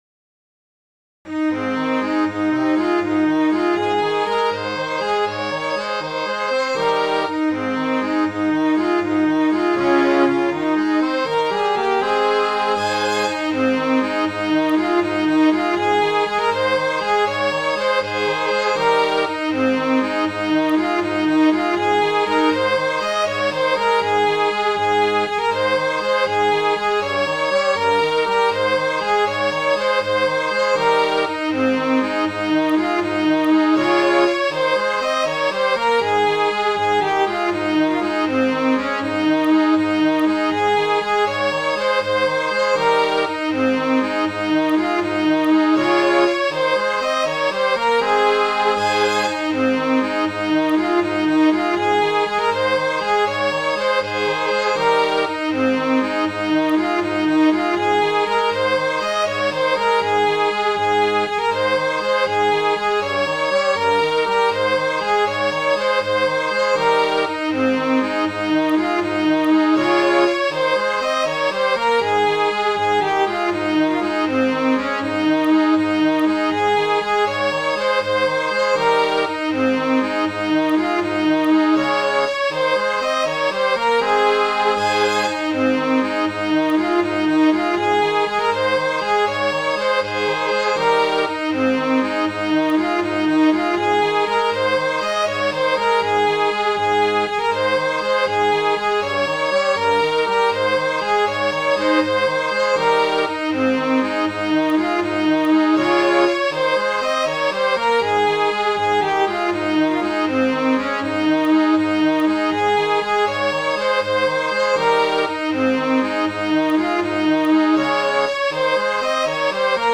as an ancient and anonymous air.
grumlie.mid.ogg